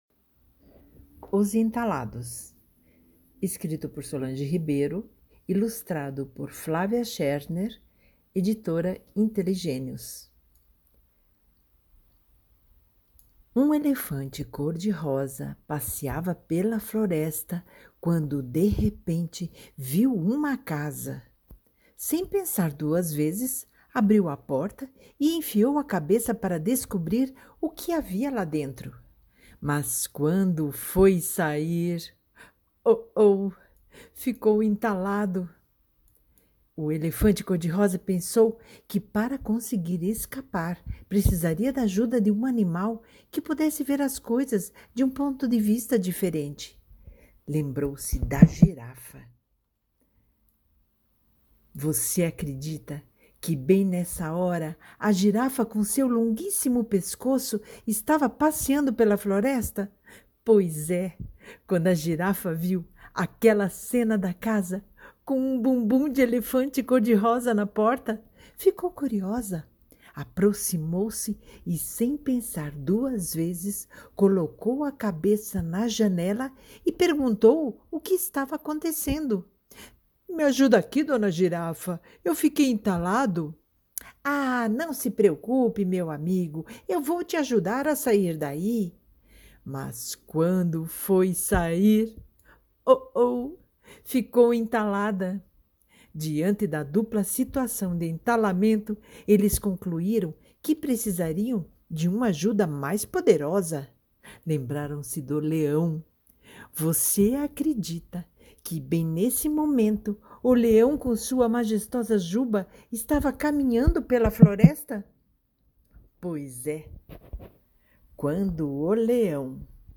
Leitura Guiada